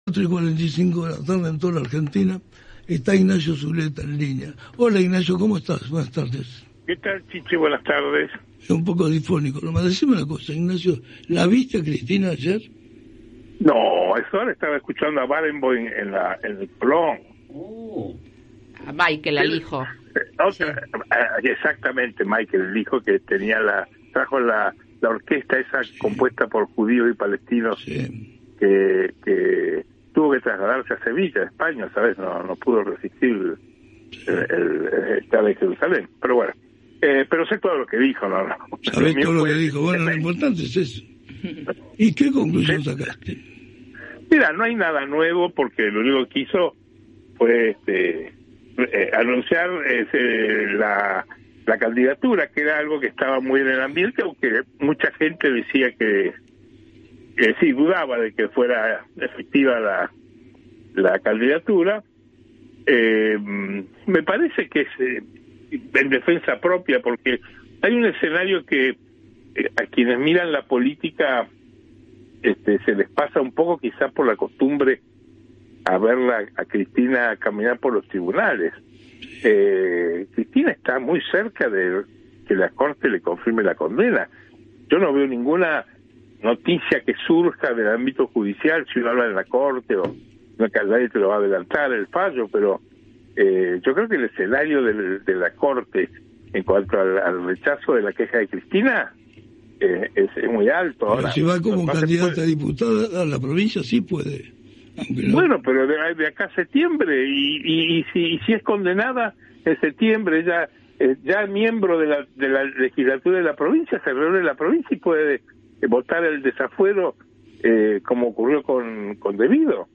Comparto un diálogo con Chiche Gelblung y la mesa del programa "Hola Chiche" que conduce por radio Del Plata. Conversamos sobre la candidatura de Cristina de Kirchner en las elecciones del 7 de septiembre en la provincia de Buenos Aires.